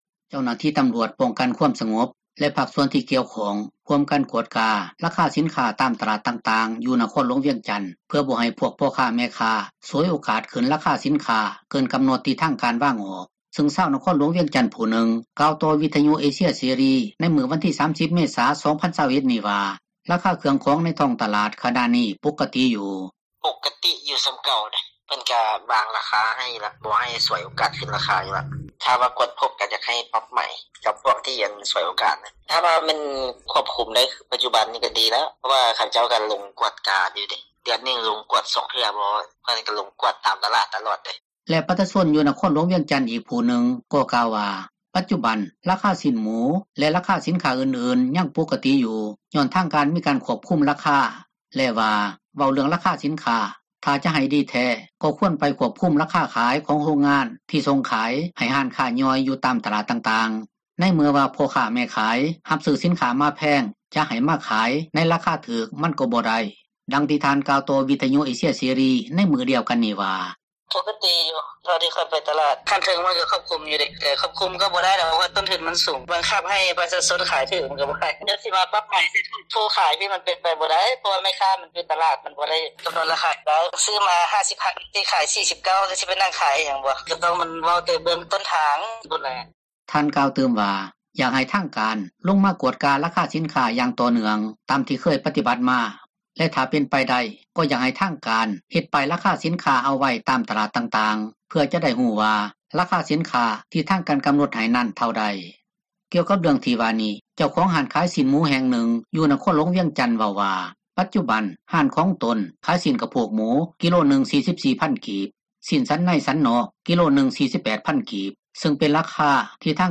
ນັກຂ່າວພົລເມືອງ